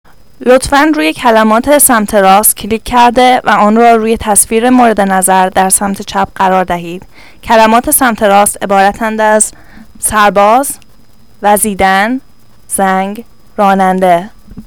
Listen to directions and the words pronounced!